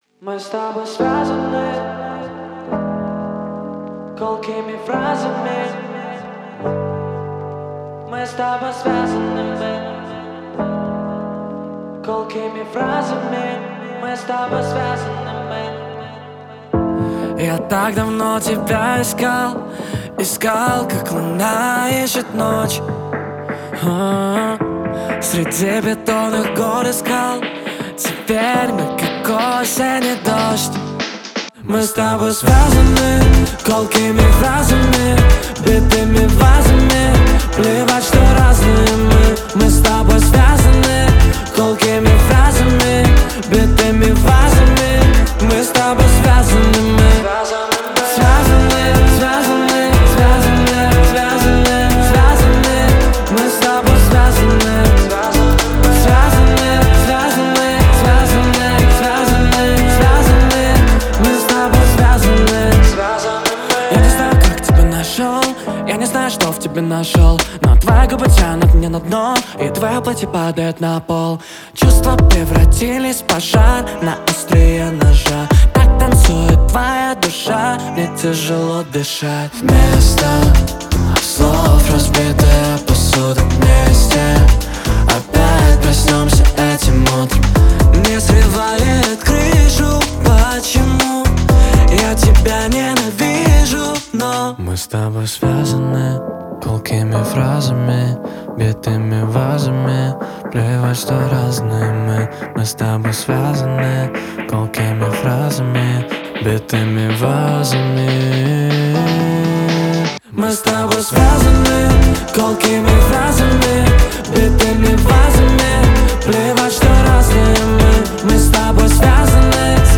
Исполнитель джазовой, соул и традиционной поп  музыки.